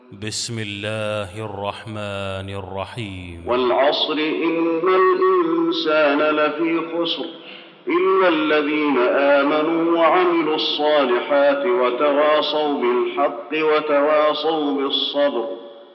المكان: المسجد النبوي العصر